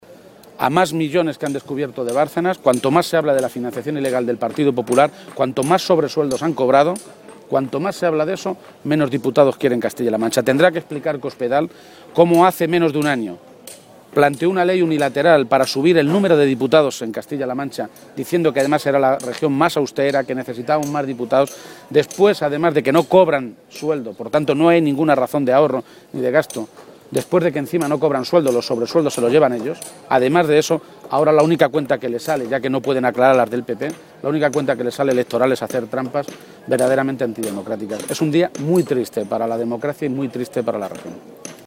Emiliano García-Page, Secretario General del PSOE de Castilla-La Mancha, frente al Palacio de Fuensalida
Cortes de audio de la rueda de prensa